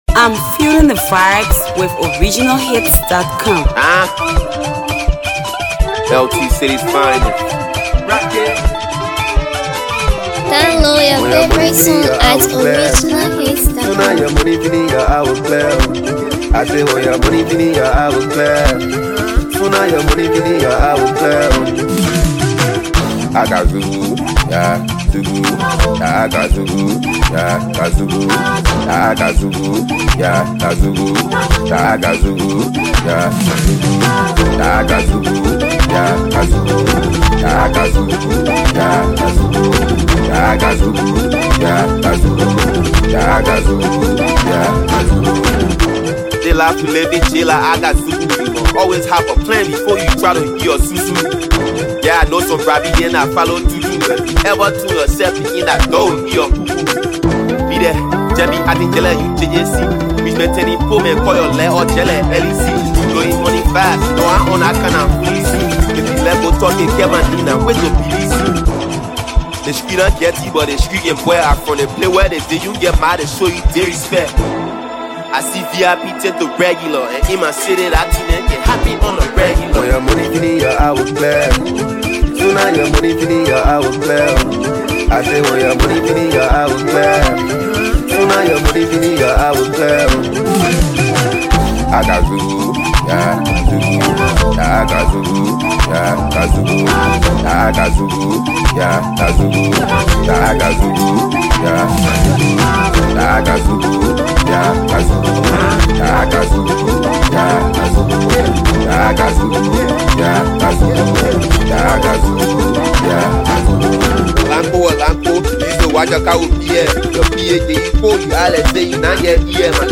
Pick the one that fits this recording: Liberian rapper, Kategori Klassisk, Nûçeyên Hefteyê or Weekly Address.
Liberian rapper